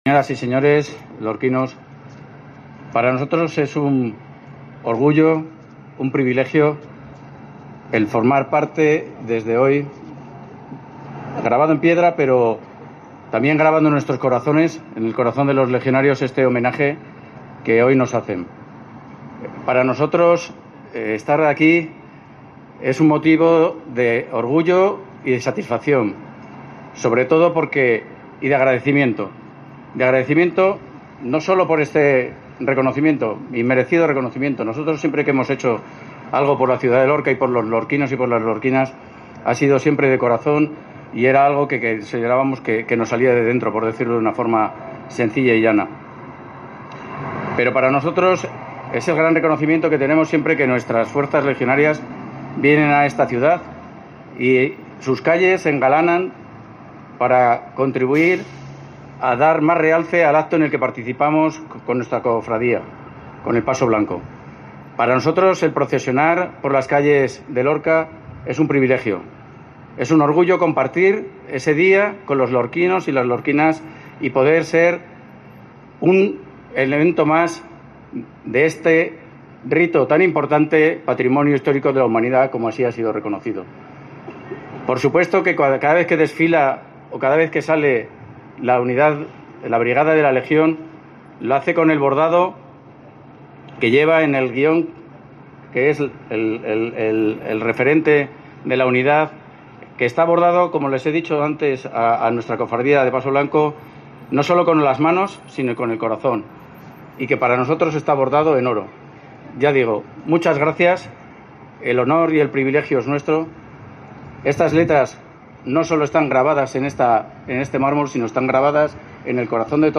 José Agustín Carrera Postigo, General Jefe de la Brigada “Rey Alfonso XIII”, II de la Legión